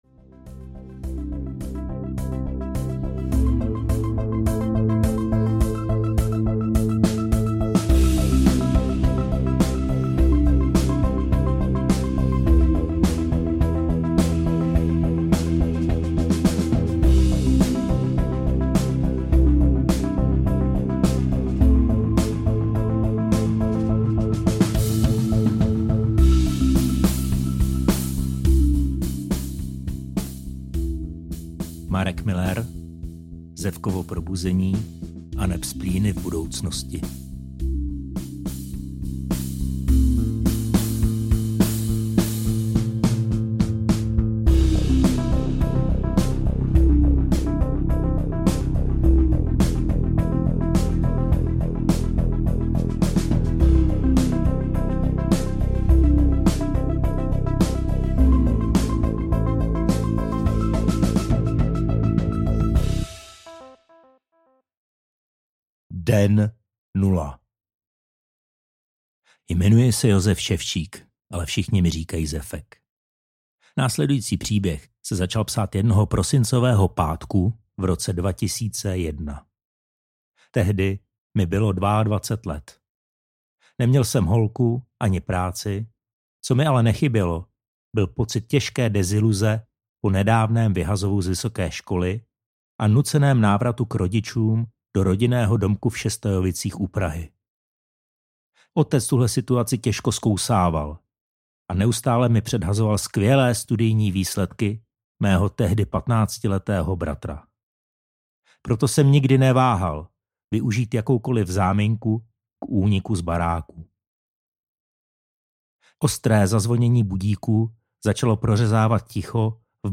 Zefkovo probuzení aneb Splíny (v) budoucnosti audiokniha
Ukázka z knihy